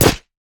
damage_wolf2.ogg